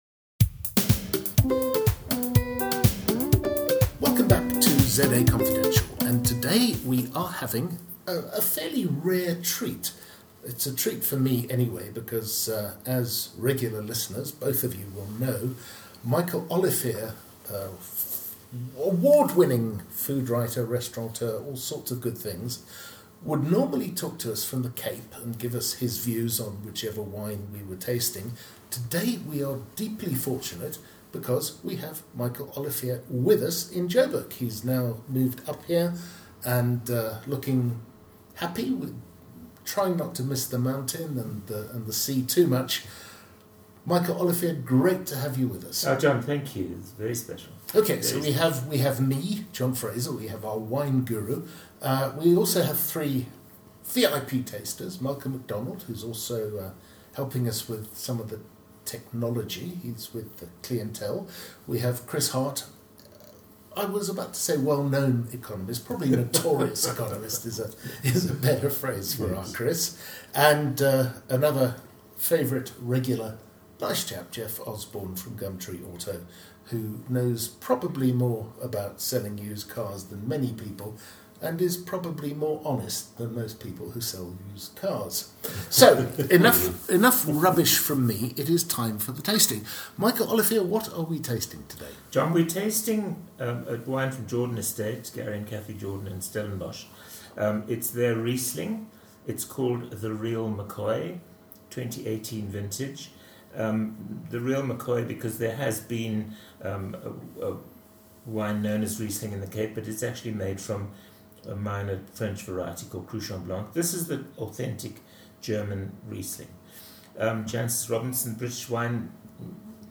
We are back. Another mildly anarchic but nonetheless serious wine tasting with the impressive The Real McCoy Riesling from Jordan in Stellenbosch.
This is followed by a chat on wine marketing.